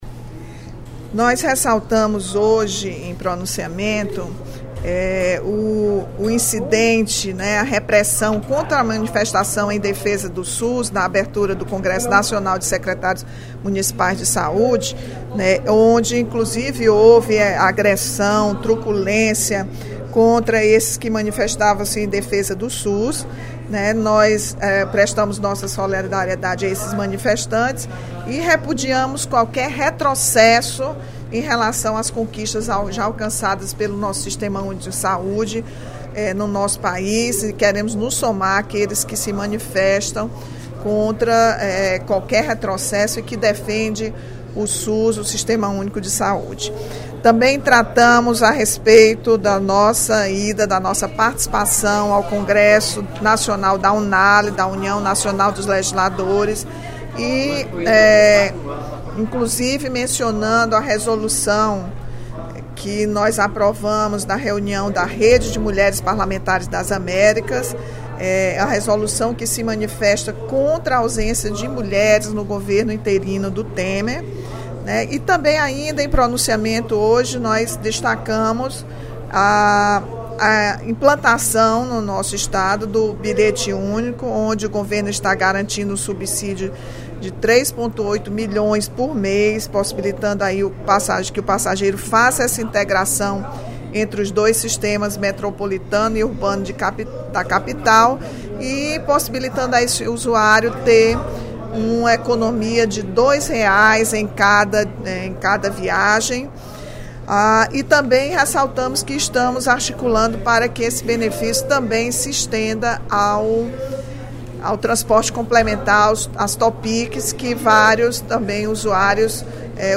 A deputada Rachel Marques (PT) ressaltou, durante o primeiro expediente da sessão plenária desta sexta-feira (03/06), o incidente ocorrido na abertura do Congresso Nacional de Secretários Municipais de Saúde.